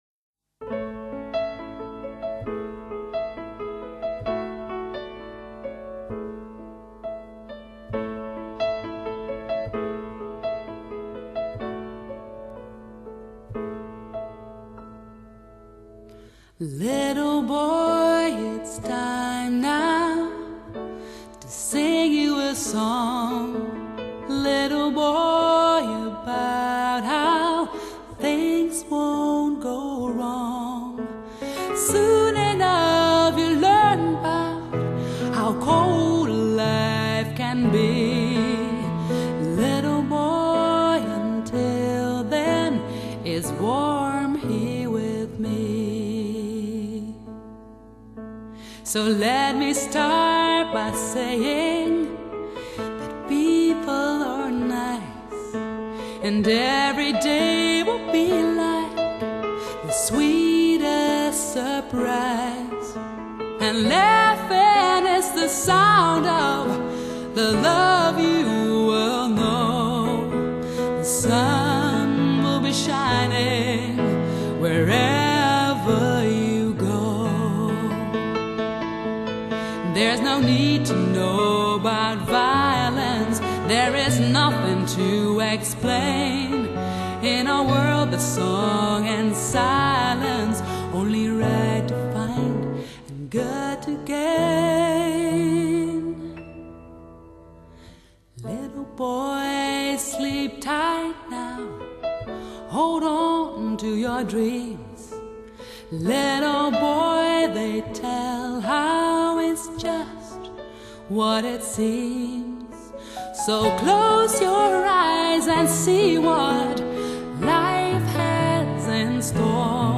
發燒極致女聲
Analog recording directly to DSD!
這錄音效果一流。
hybridSA-CD，二聲道
錄音時，使用不同型號Schoeps話筒。